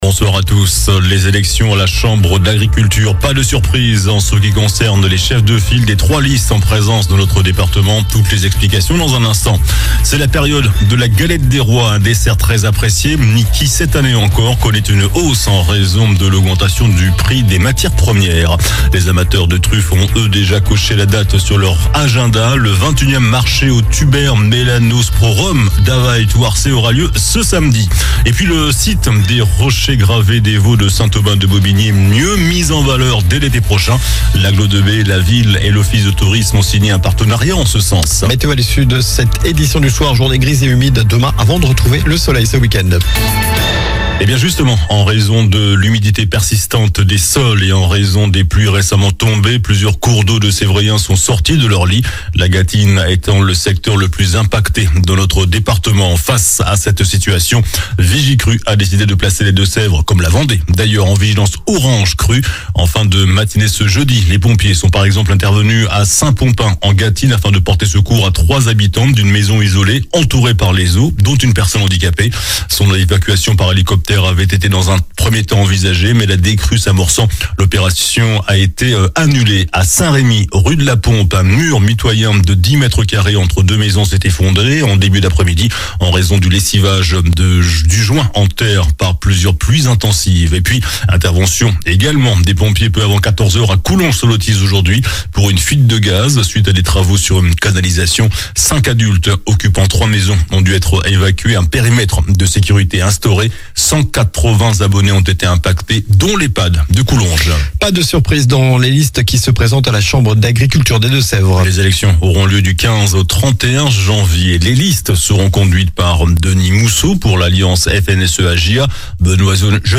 JOURNAL DU JEUDI 09 JANVIER ( SOIR )